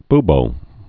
(bbō, by-)